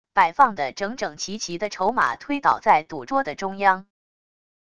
摆放的整整齐齐的筹码推倒在赌桌的中央wav音频